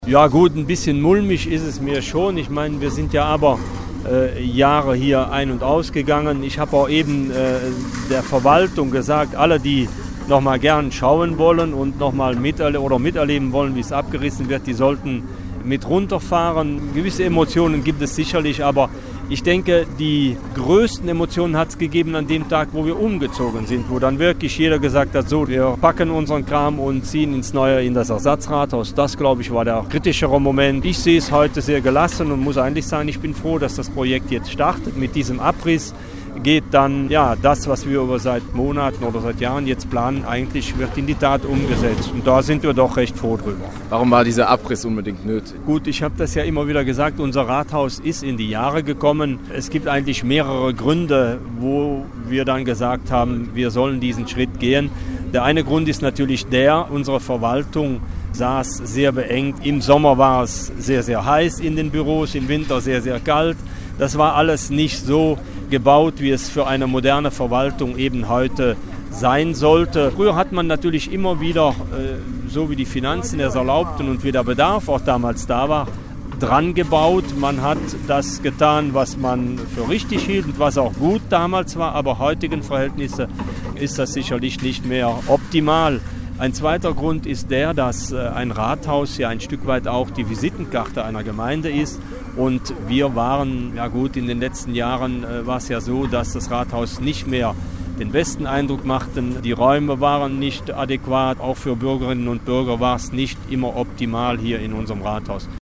Am Dienstag ist das Büllinger Rathauses zerlegt worden. Schon bald wird der Neubau errichtet. 2,5 Millionen Euro kostet das Projekt. „Eine absolut notwendige Angelegenheit“, sagt Büllingens Bürgermeister Friedhelm Wirtz.